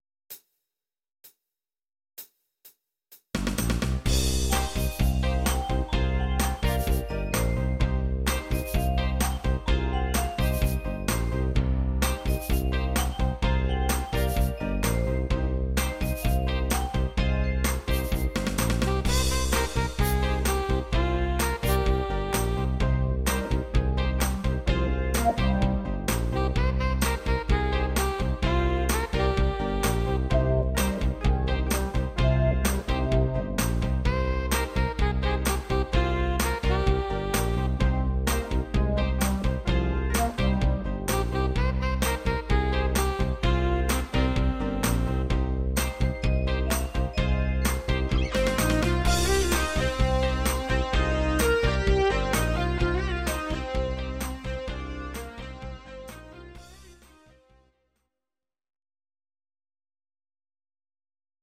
Audio Recordings based on Midi-files
Pop, Rock, 1990s